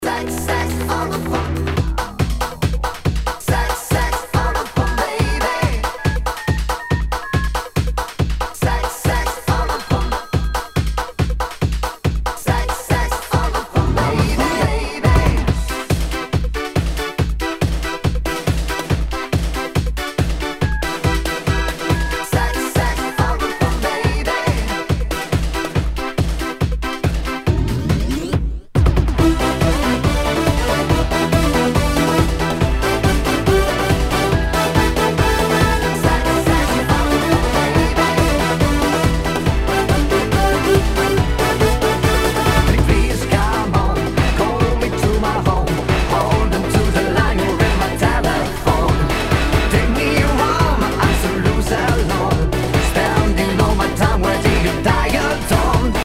HOUSE/TECHNO/ELECTRO
ナイス！ユーロビート！
プレイ可能ですが盤に歪みあり（相性が悪いと音飛びするかもしれません）全体にチリノイズが入ります